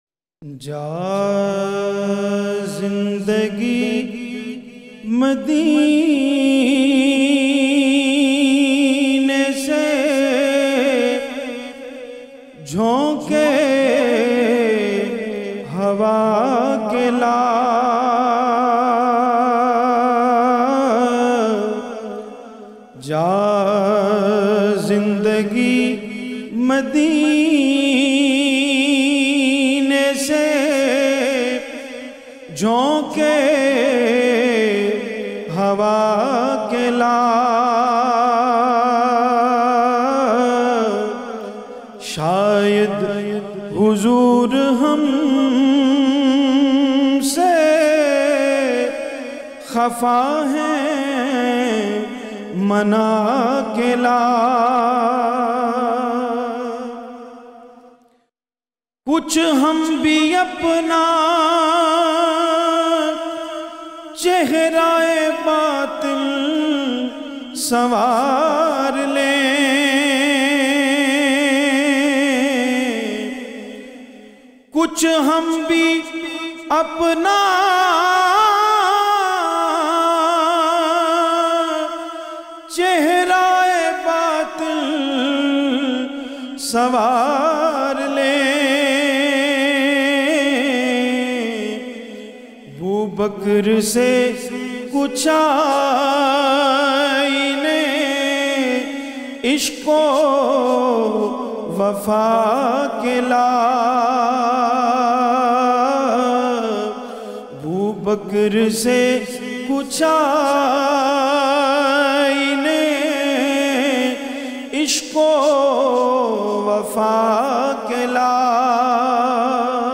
has very sweet and magical voice with wonderful control